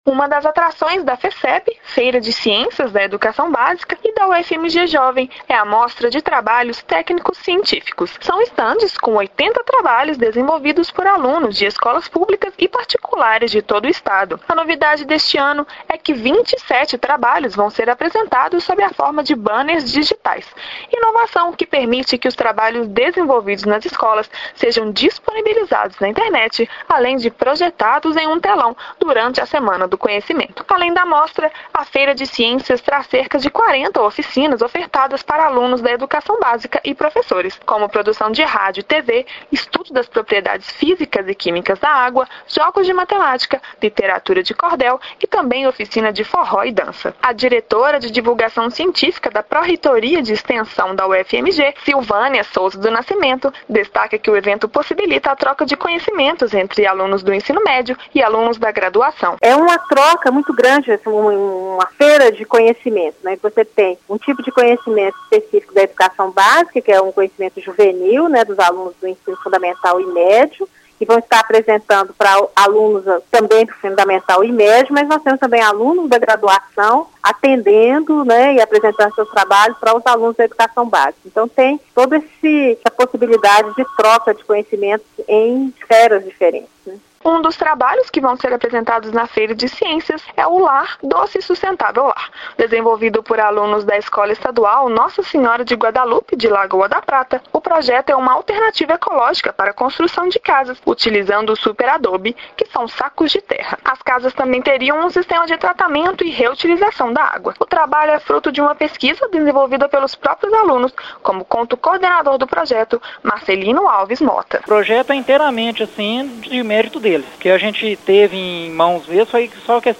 matéria da Rádio UFMG Educativa e saiba mais.